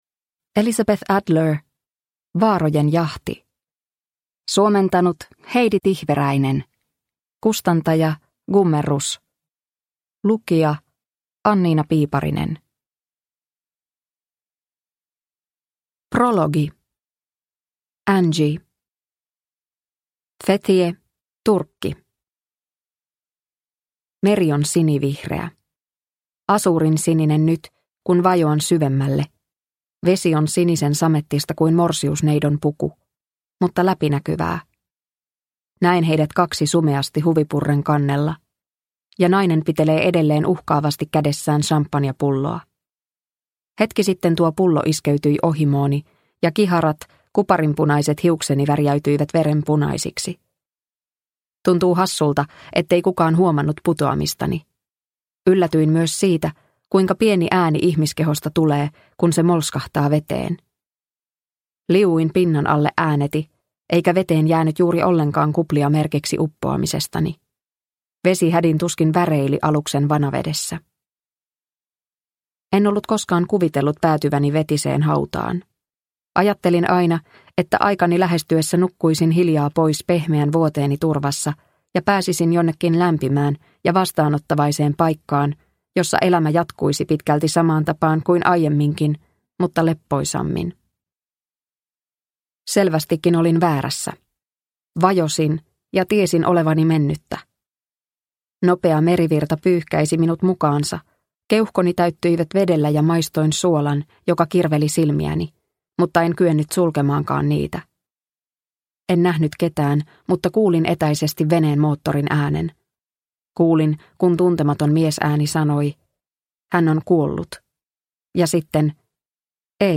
Vaarojen jahti – Ljudbok – Laddas ner